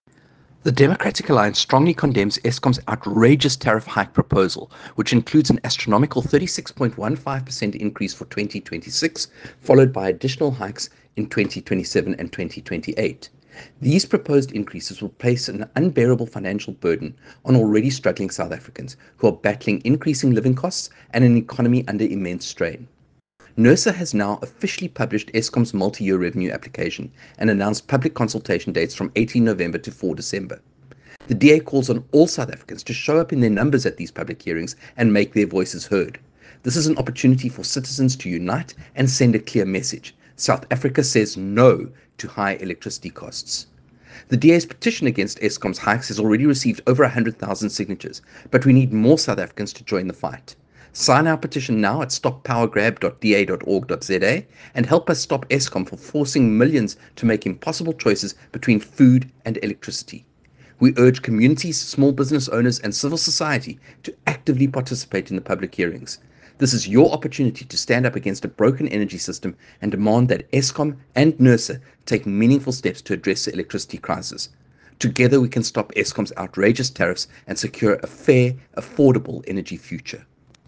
Issued by Kevin Mileham MP – DA Spokesperson on Energy and Electricity
soundbite by Kevin Mileham MP.